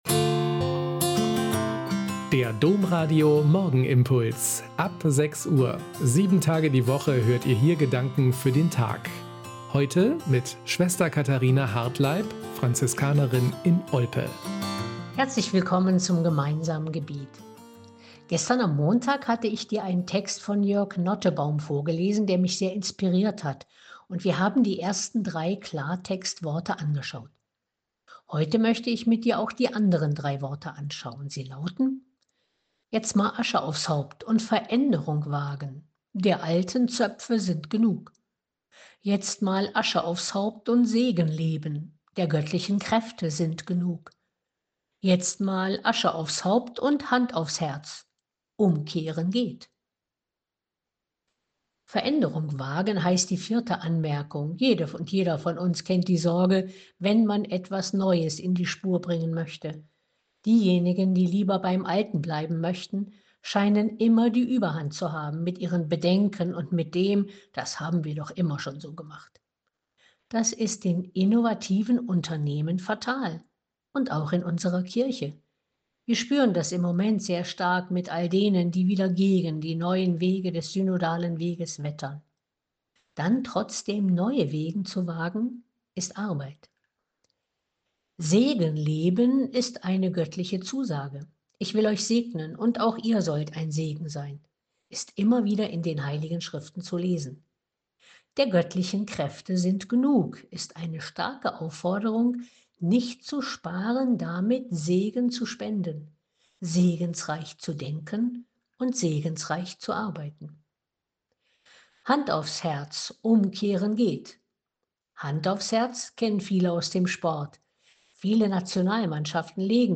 Morgenimpuls